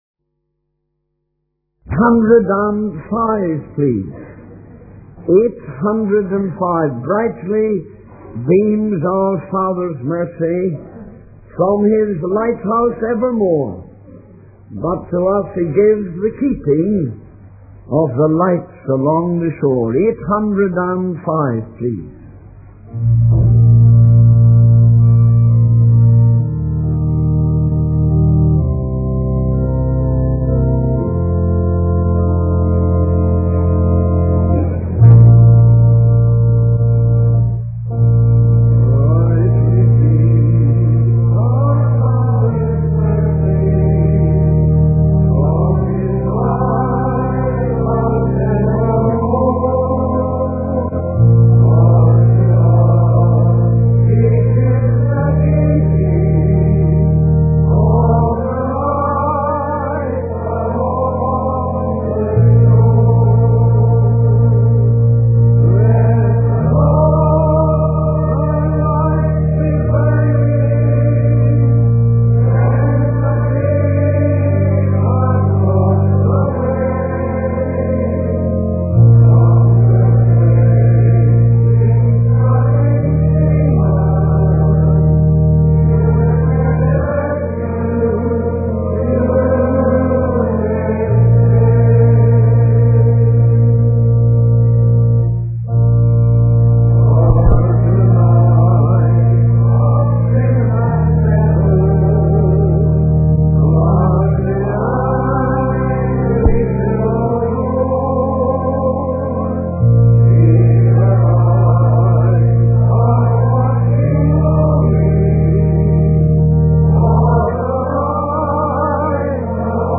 The sermon underscores that no one is beyond God's reach for salvation, and that true peace comes from a relationship with the Most High.